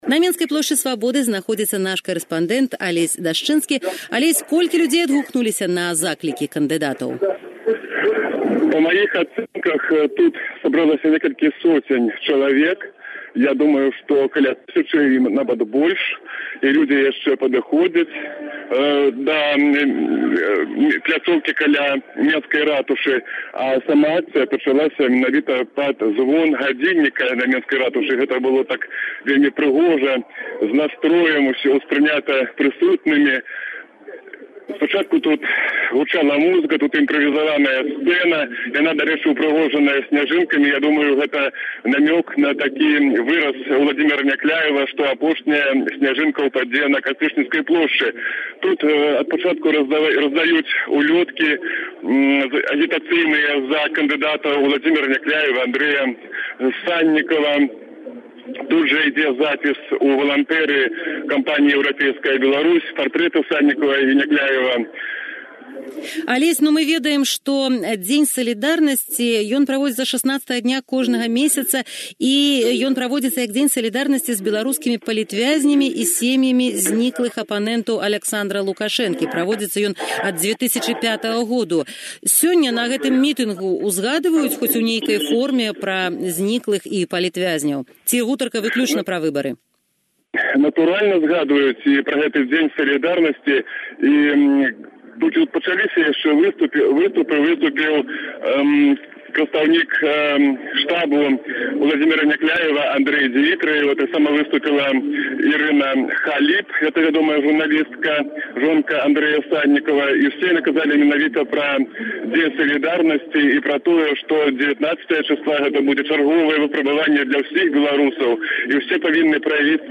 перадае зь месца падзеяў